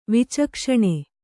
♪ vicakṣaṇe